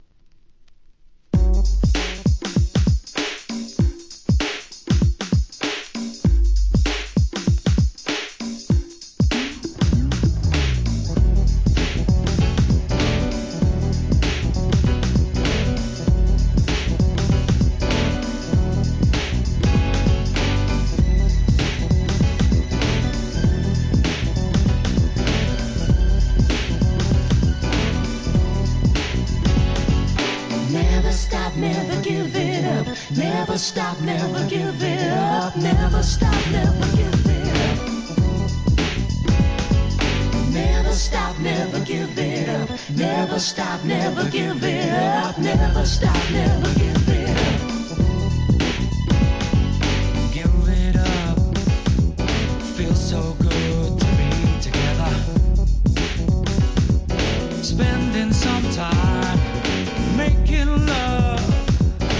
HIP HOP/R&B
後の大ヒットですが、男性ヴォーカルで1990年のリリース!!